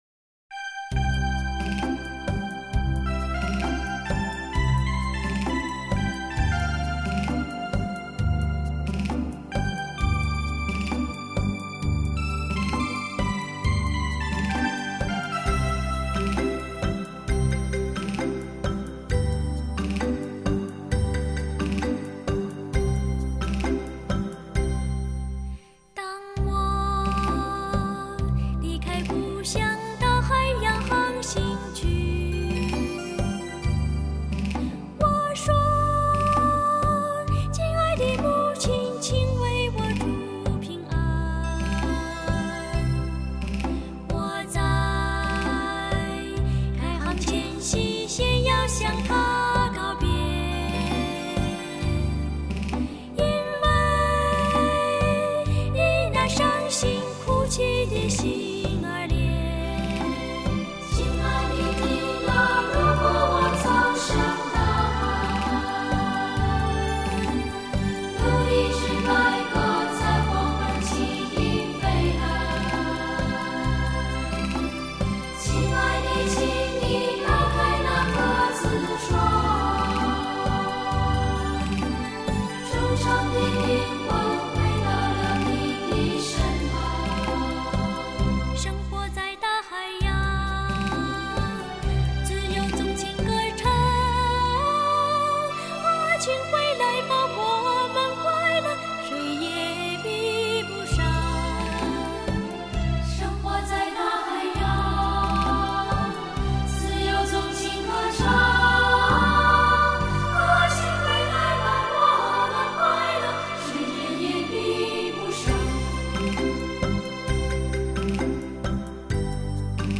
童声合唱是一种广受欢迎的形式，它包含错落的声部构成
国内少有的专业童声合唱团体，团员均属于“一时之选”